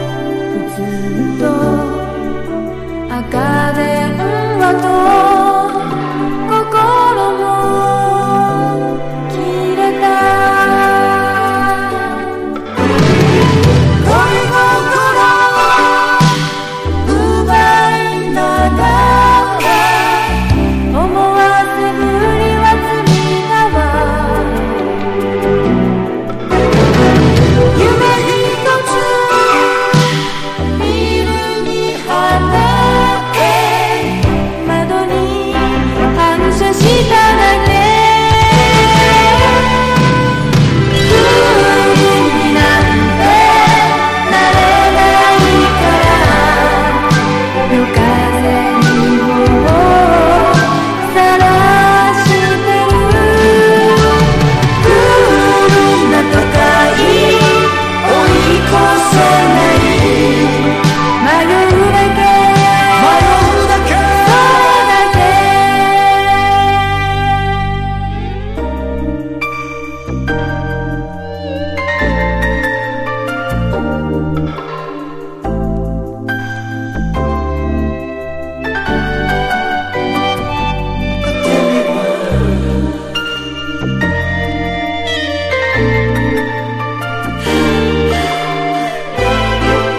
80Sサウンドに彩られたスローバラードです。
POP